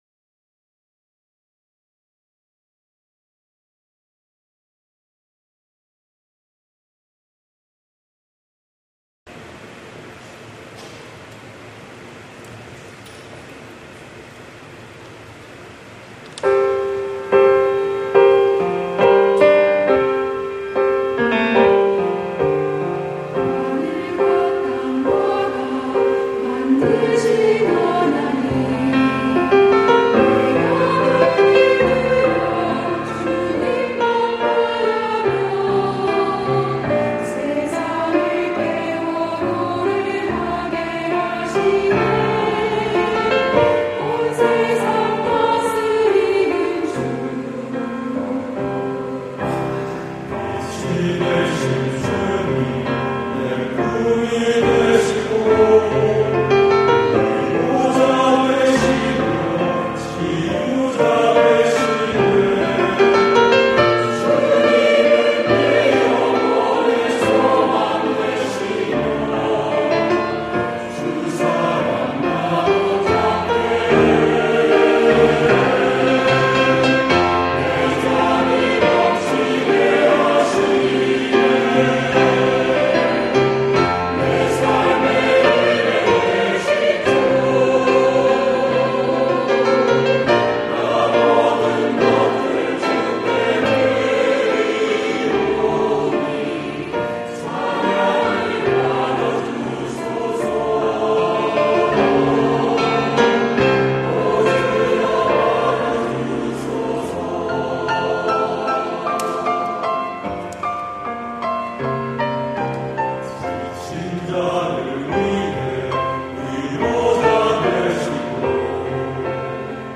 내 삶에 찬양되신 주 > 찬양영상